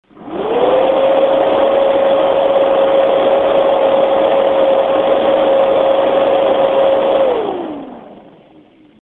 frostytech acoustic sampling chamber - high fan speed
standard waveform view of a 10 second recording. click on the headphones icon to listen to an mp3 recording of this heatsink in operation. the fan is running at full rpm, or stock speeds, in this test.
ASUS Starice high 67.4 dB
With the fan speed controller removed or set to full speed, the fan kicks in and roars with a 67.4 dBA racket.